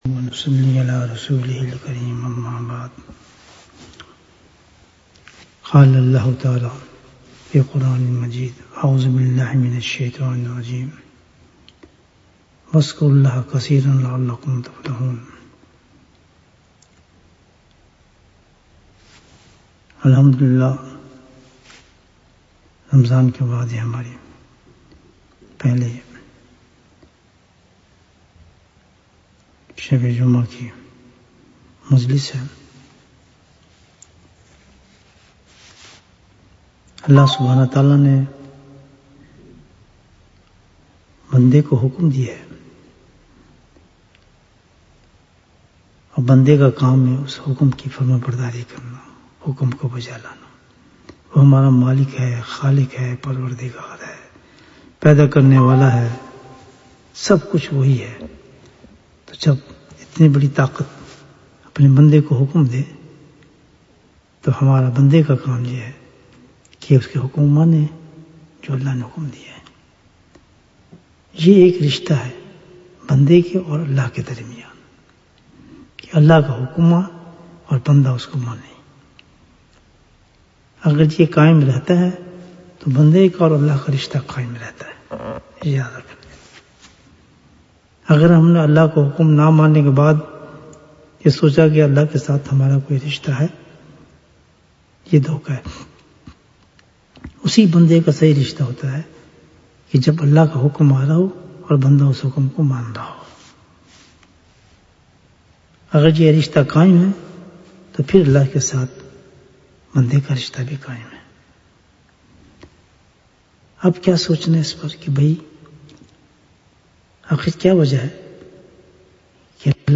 Bayan, 17 minutes 20th May, 2021 Click for English Download Audio Comments Why Does a Person’s Relationship with Allah ta'ala Become Weak? Alhamdulillah, this is the first night of our Jumu’ah gatherings after Ramadhan.